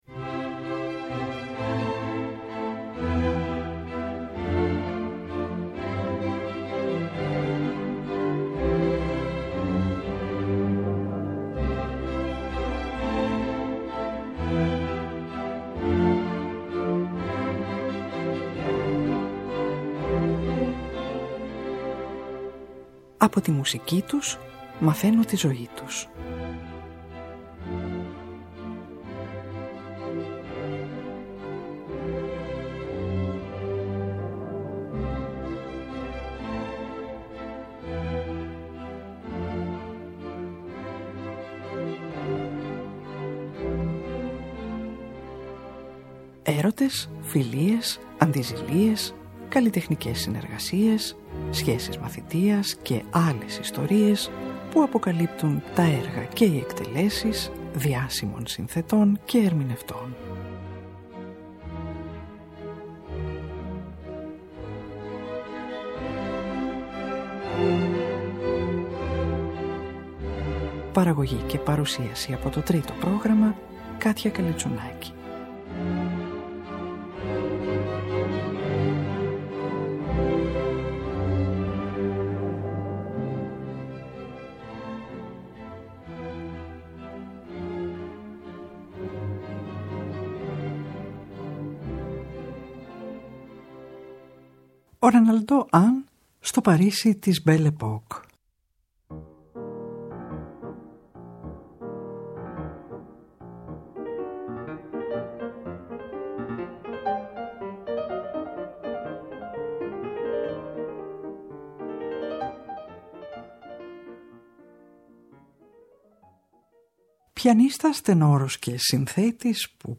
Από τα τραγούδια του ακούγονται το Si mes vers avaient des ailes σε ποίηση Victor Hugo που έγραψε 14 ετών το 1888,